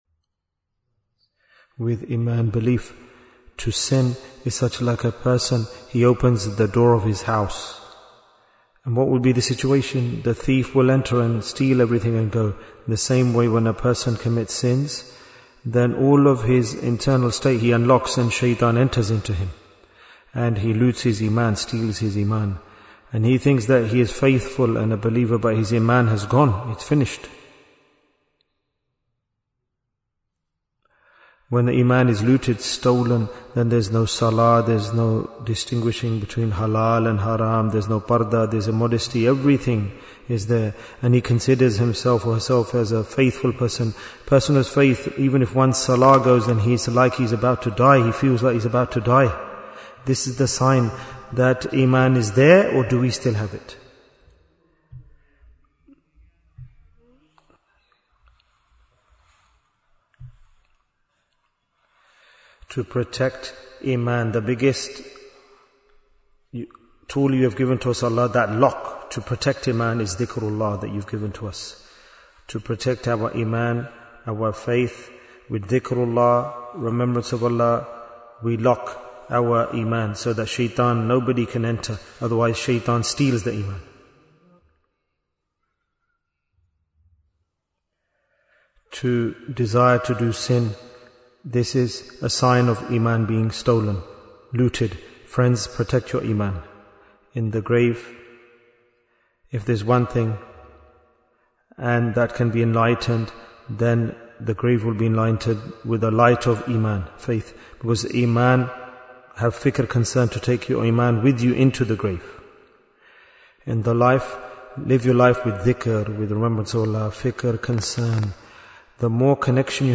Jewels of Ramadhan 2026 - Episode 9 Bayan, 19 minutes22nd February, 2026